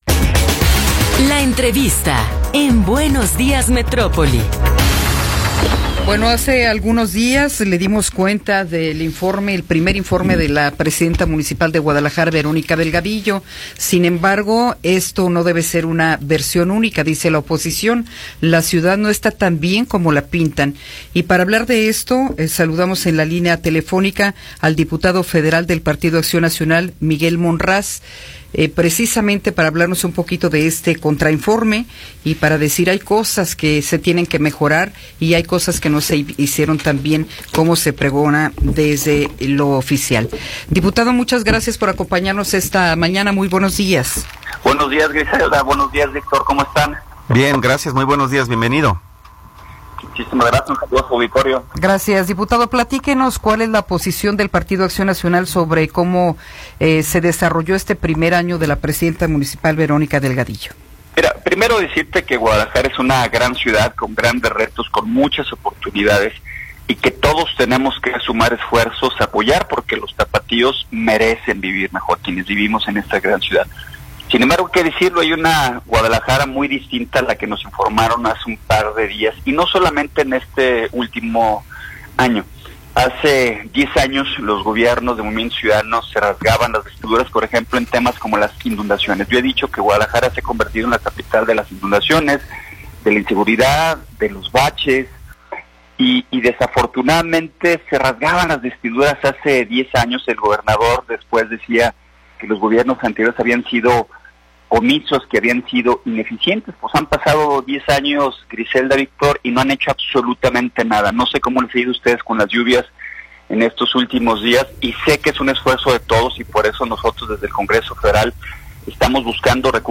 Entrevista con Miguel Ángel Monraz